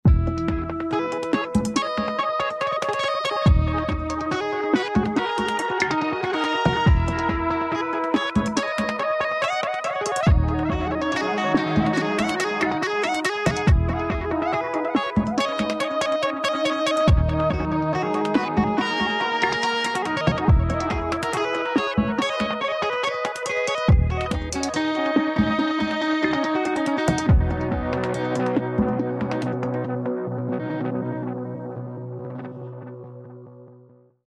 In this demo, we played a simple guitar melody through the Blocks A preset to introduce some wild musical glitches and tempo-synced chops.
Chroma Console is also doing a lot here - the guitar is hitting Microcosm first, so all of Microcosm's effects are getting further affected by Chroma Console. This adds some extra texture and movement, and the saturation effects feel they glue the melody together more tightly.